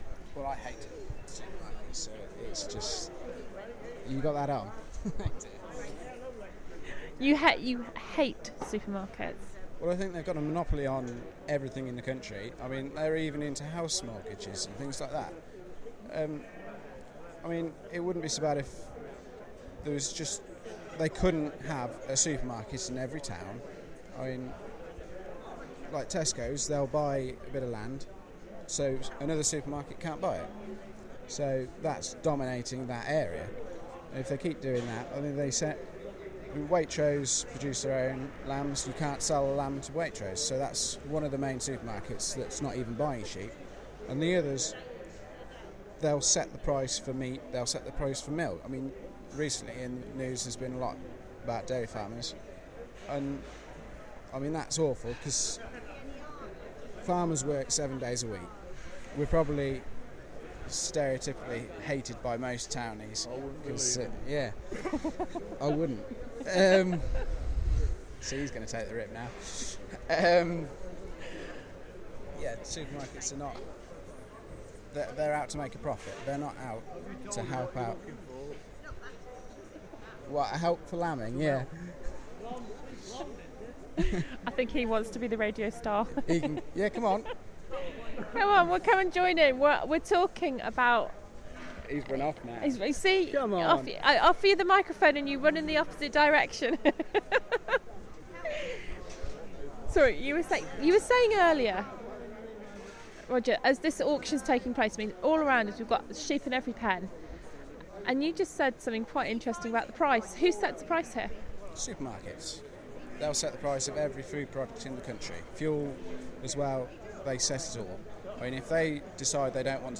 Melton Mowbray Farmers Market chatting to an infuriated farmer who never knows what he will earn this week.